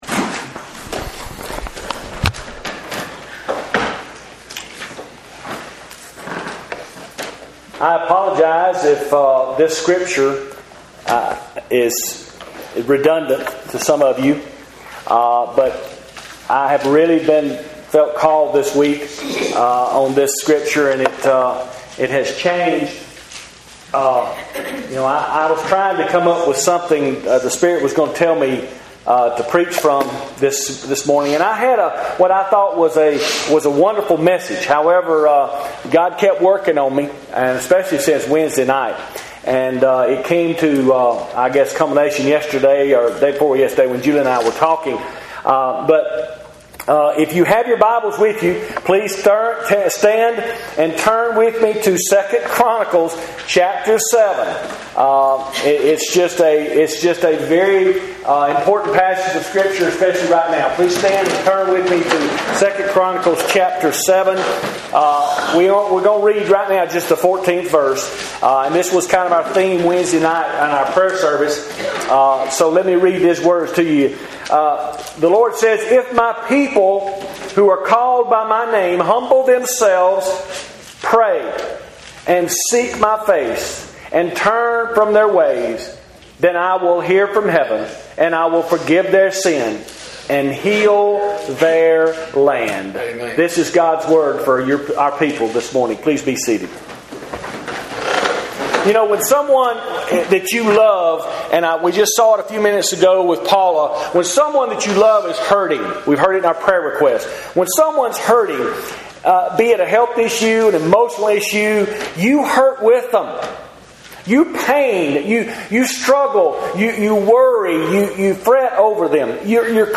Sermon Archives — Saint Paul United Methodist Church